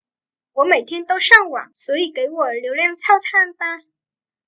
Wǒ měitiān dōu shàngwǎng, suǒyǐ gěi wǒ liúliàng tàocān ba.
Úa mẩy then tâu sang goảng, xúa ỷ cấy ủa liếu leng thao chan pa.